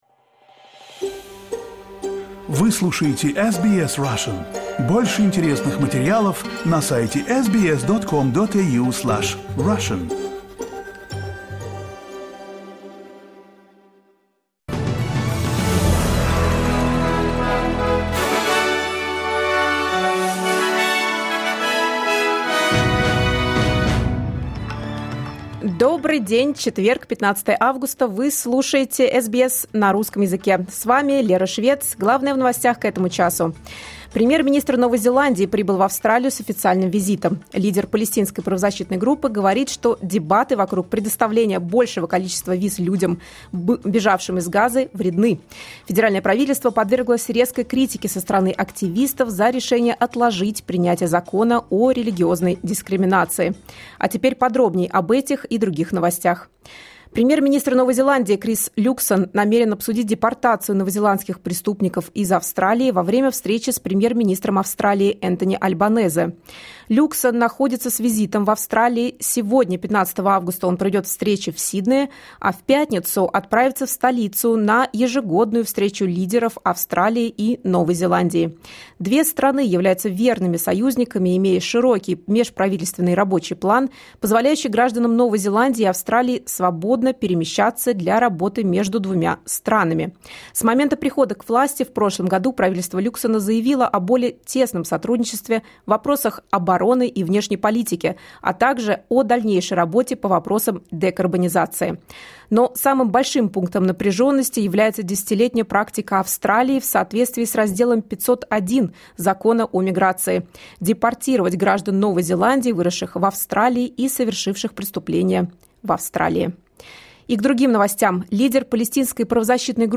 Новости SBS на русском языке — 15.08.2024